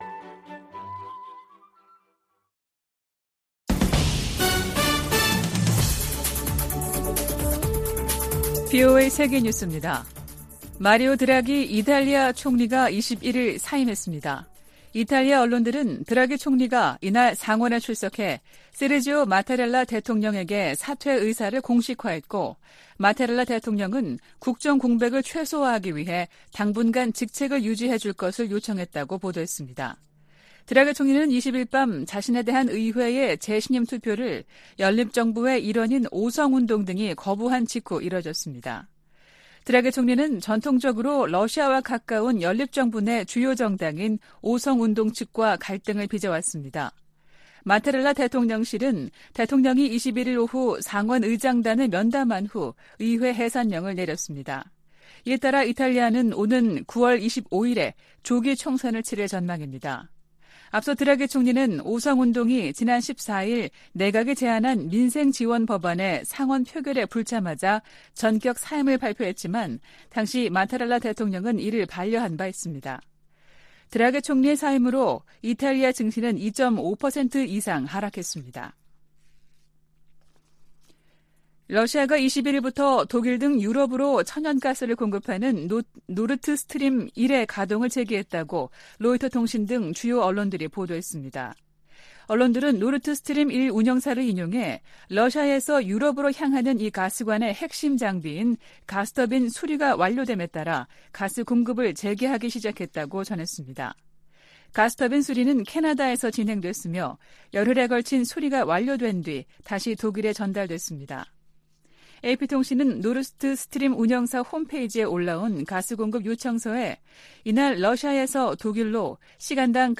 VOA 한국어 아침 뉴스 프로그램 '워싱턴 뉴스 광장' 2022년 7월 22일 방송입니다. 북한은 사이버 활동으로 수익을 추구하는 범죄조직이며, 이를 차단하는 것이 미국의 최우선 과제라고 백악관 고위 관리가 밝혔습니다. 백악관 국가안보회의(NSC) 측은 일본의 역내 안보 활동 확대가 북한 등 위협에 대응하는 데 큰 도움이 될 것이라고 말했습니다. 유럽연합(EU)은 북한이 우크라이나 내 친러시아 공화국들을 승인한 데 대해 국제법 위반이라고 비판했습니다.